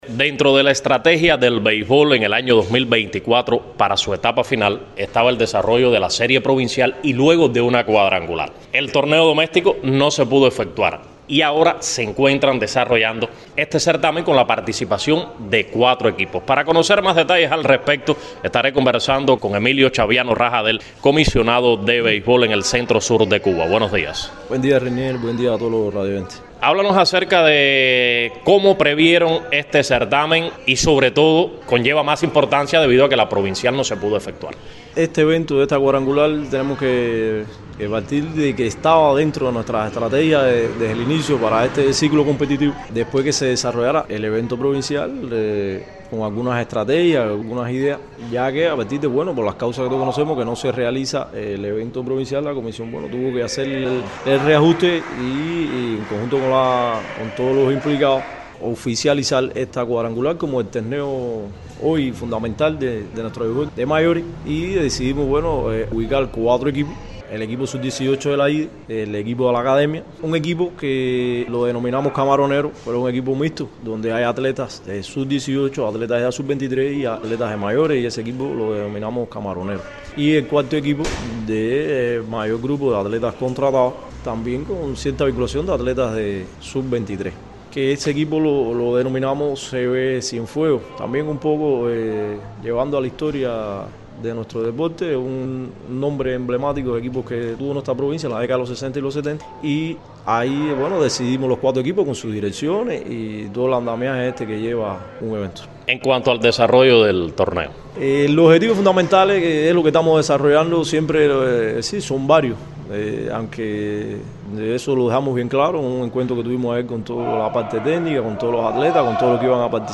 En diálogo con la emisora radio Ciudad del Mar